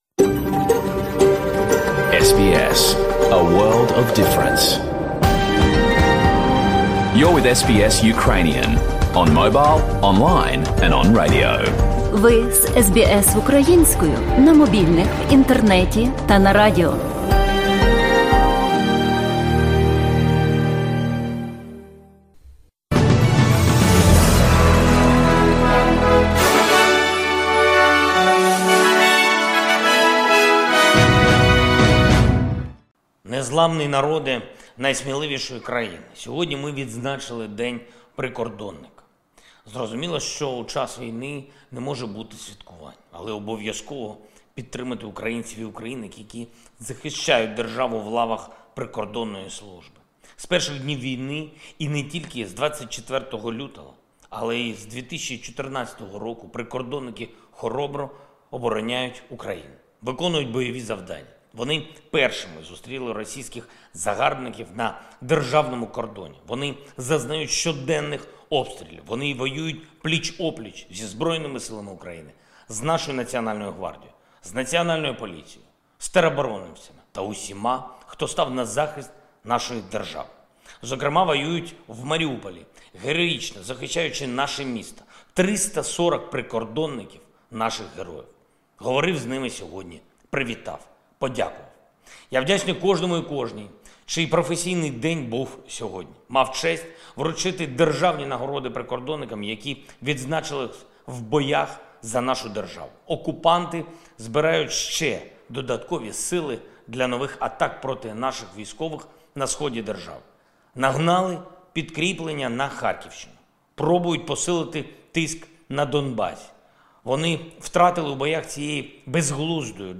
Звернення Президента України Володимира Зеленського